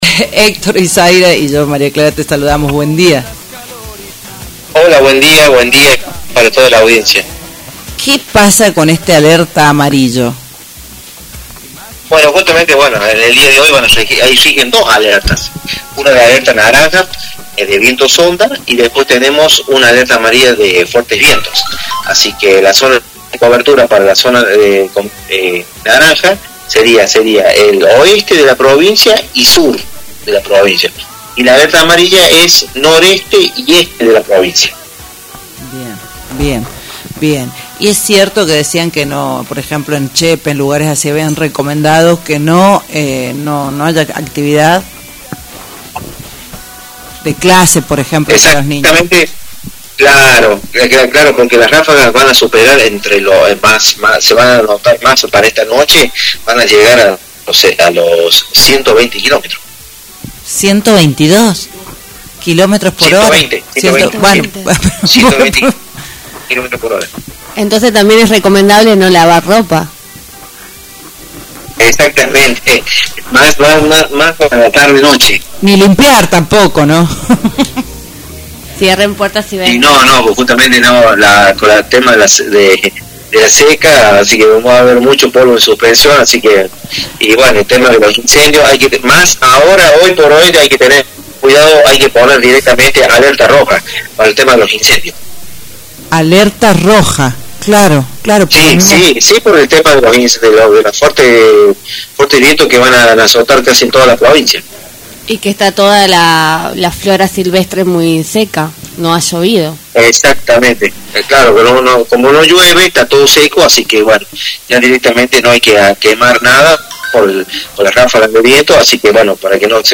En comunicación con Radio Libertad